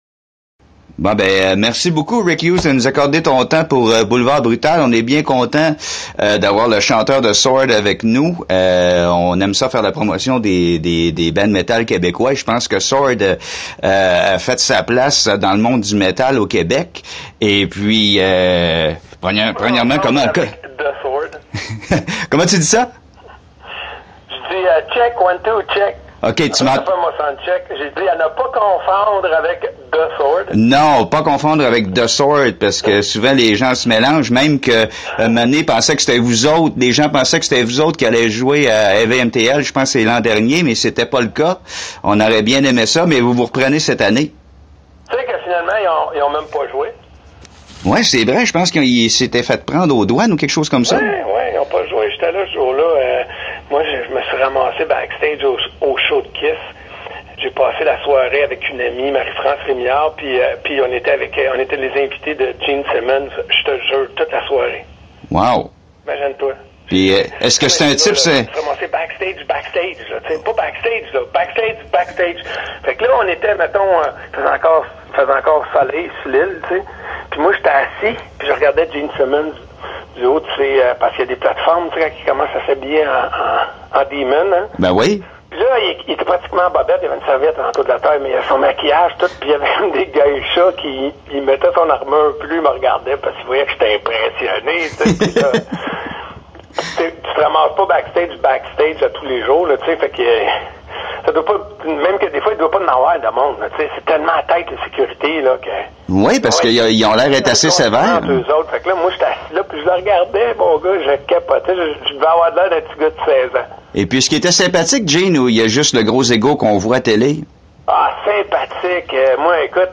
C’est plus ou moins une entrevue, c’est plus comme deux gars qui jasent autour d’une bonne grosse biére frette…